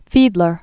(fēdlər), Arthur 1894-1979.